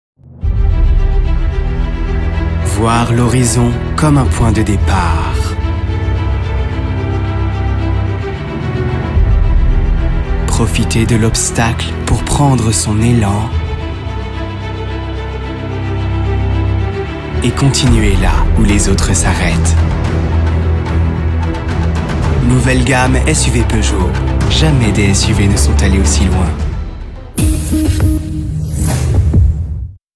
Voix off pub voiture grave, sensuel, jeune